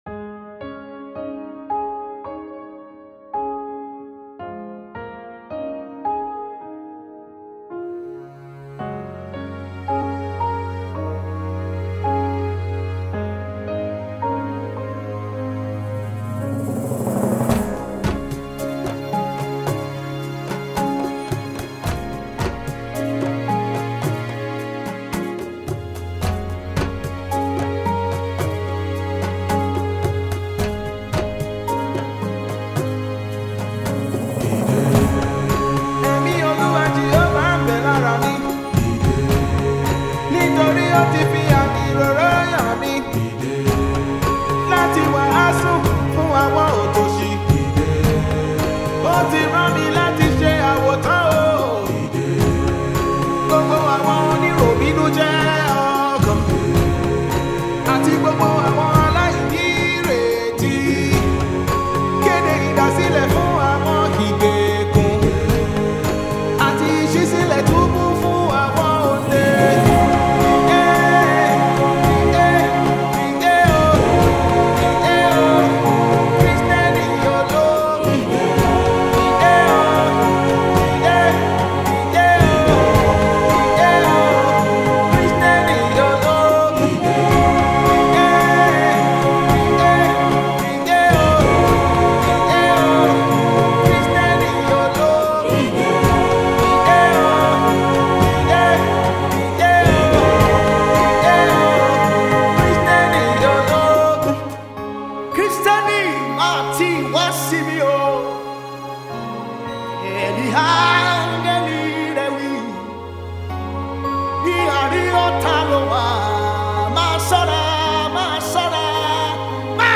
” the theme song of the trending Nigerian Christian film